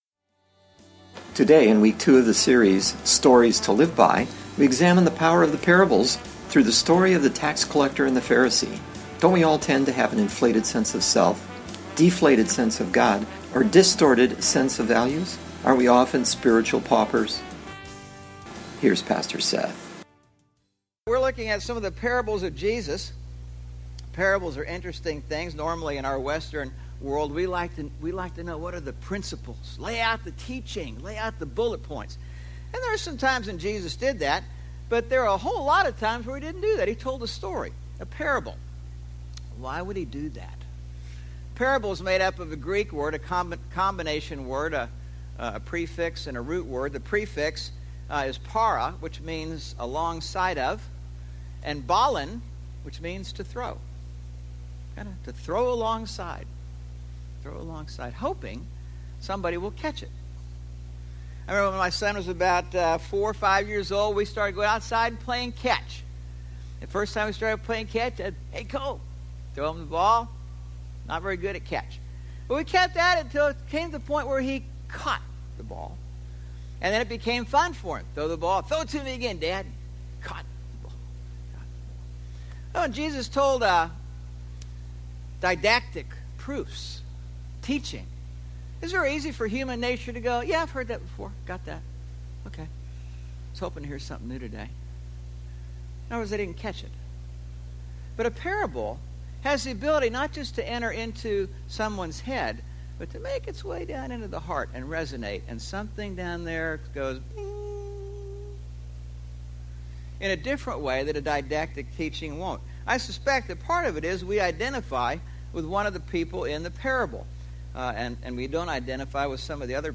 02/21/16 Sermon – Churches in Irvine, CA – Pacific Church of Irvine